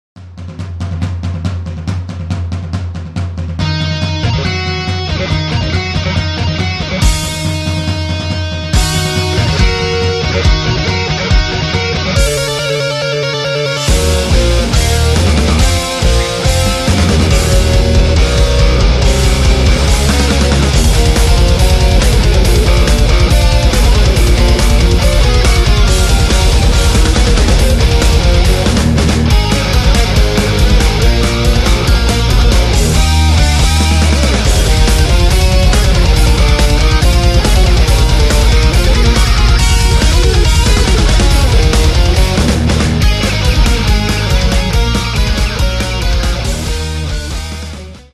Rock & Alternative
Catalogue -> Rock & Alternative -> Energy Rock